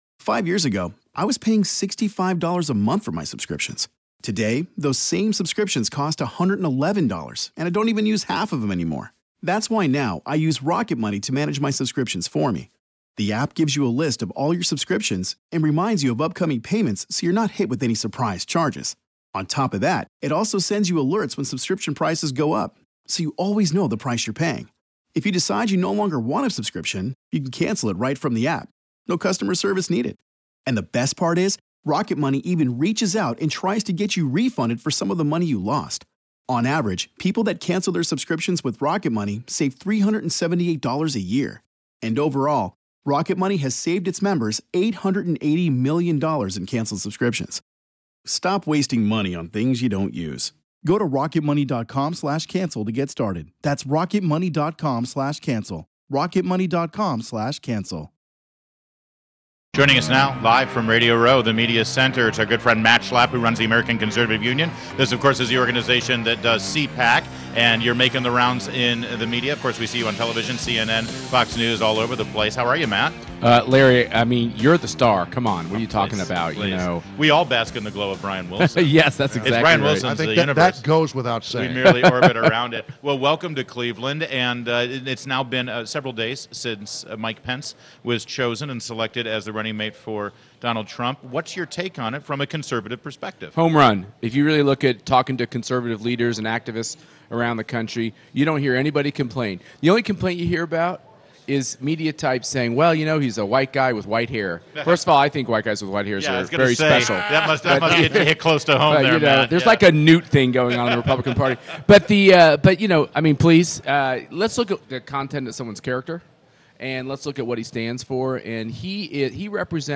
WMAL Interview - Matt Schlapp - 07.18.16